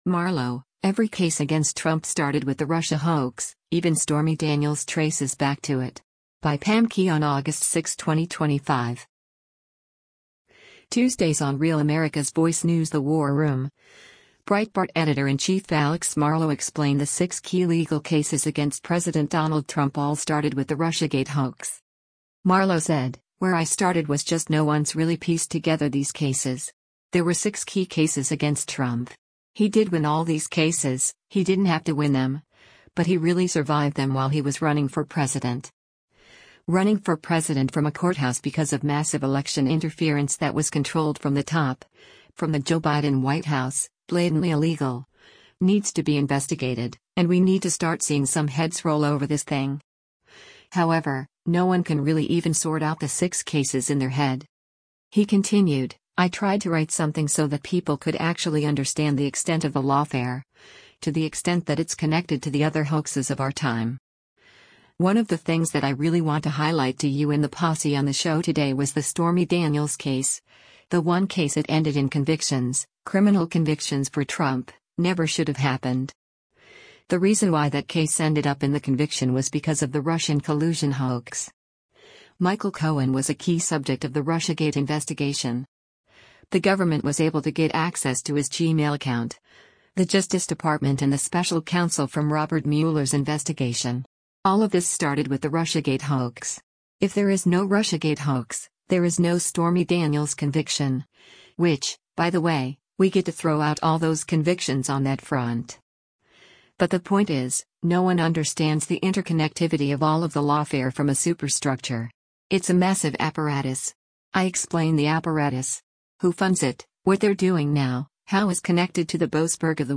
Host Steve Bannon said, “Your timing on this one is divine providence.”